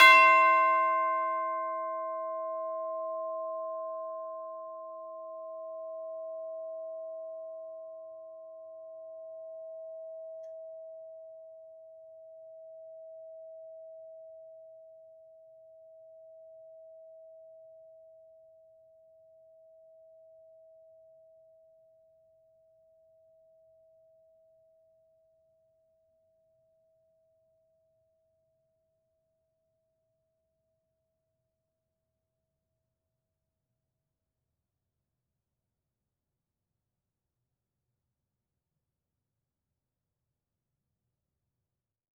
TB_hit_C5_v4_rr1.wav